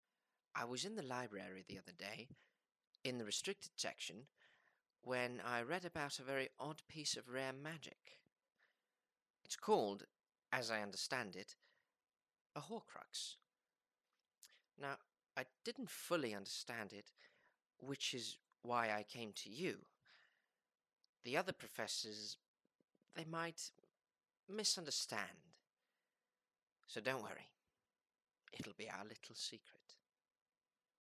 Raddprufur